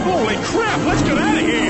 Worms speechbanks
Grenade.wav